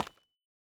Minecraft Version Minecraft Version 21w07a Latest Release | Latest Snapshot 21w07a / assets / minecraft / sounds / block / calcite / step2.ogg Compare With Compare With Latest Release | Latest Snapshot